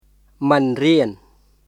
[ピアサー・クマエ　pʰiˑəsaː kʰmae]